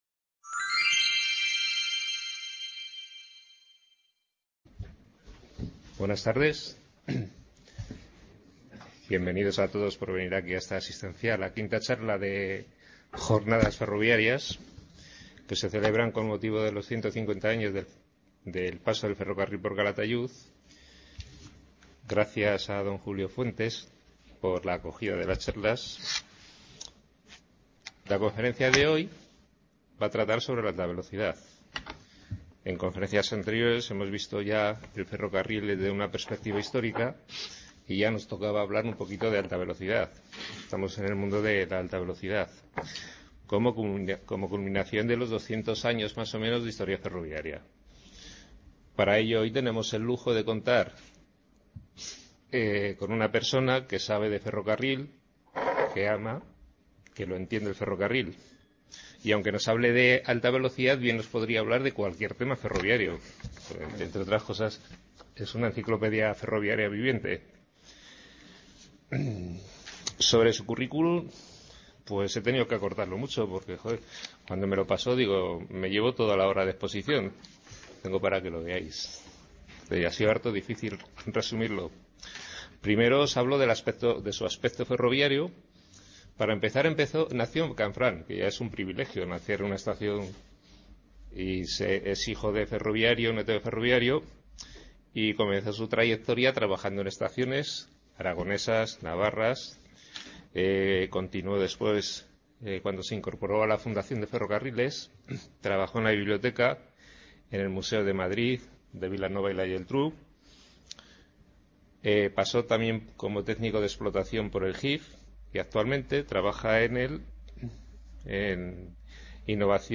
Trenes y servicios de alta velocidad en Europa. Una perspectiva histórica y de futuro Description Ciclo de seis conferencias sobre el ferrocarril, impartidas por expertos en la materia y abiertas a todo el público. Con motivo del 150 Aniversario de la llegada del ferrocarril a la Estación de Calatayud.